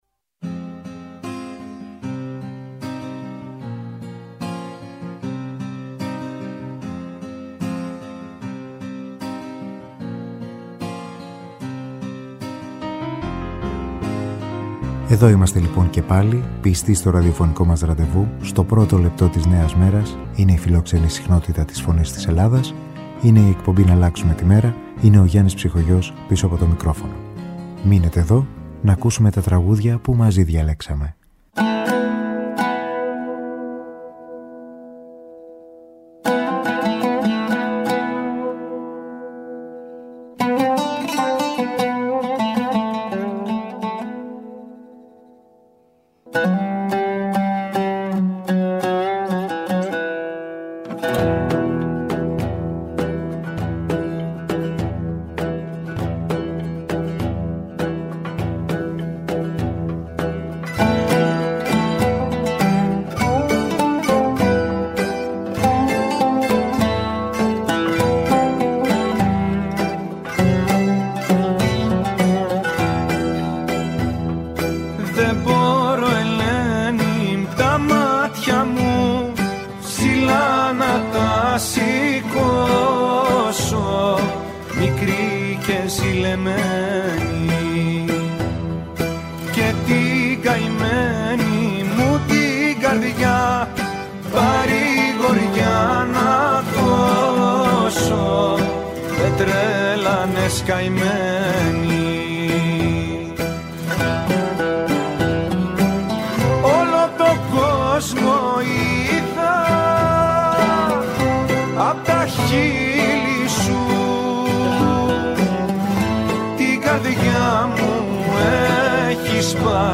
Μουσική